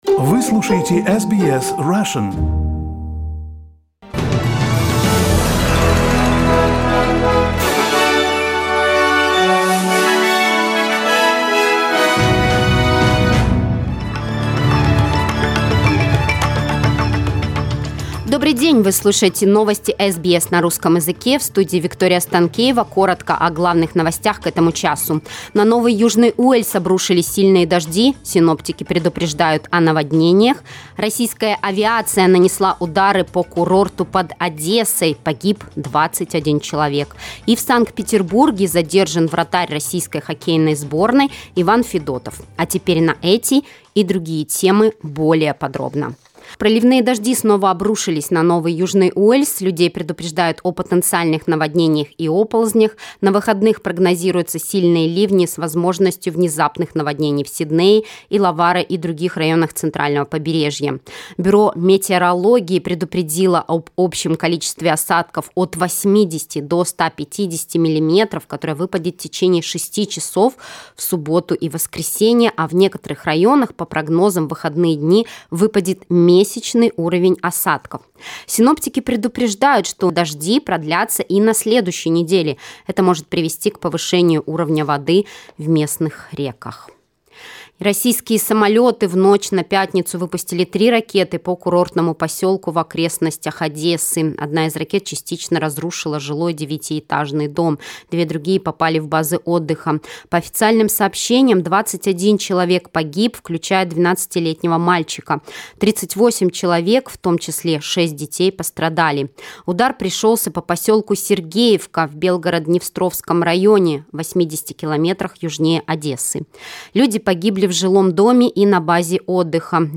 SBS News in Russian - 2.07.2022